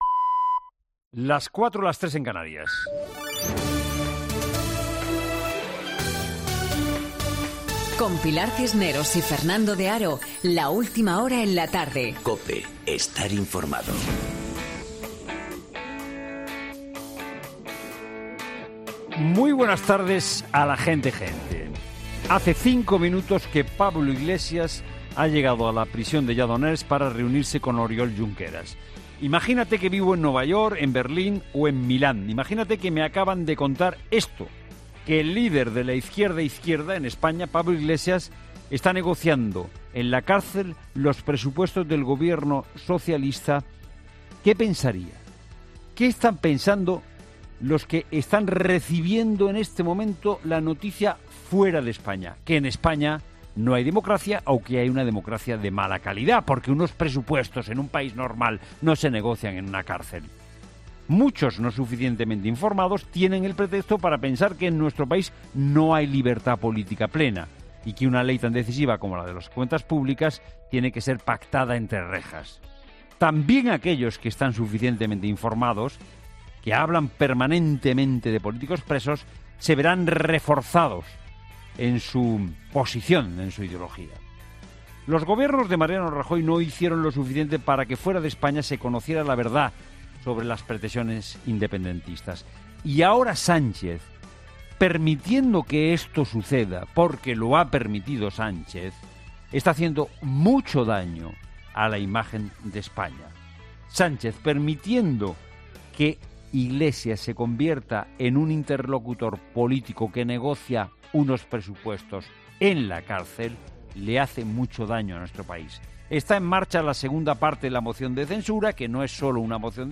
Monólogo